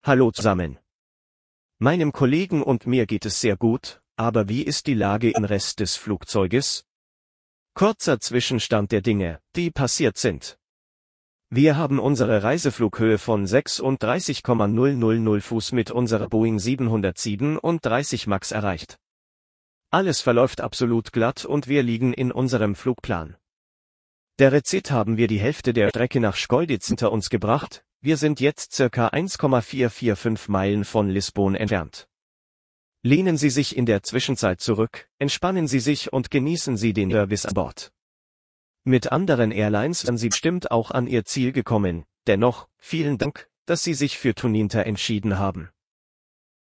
Announcements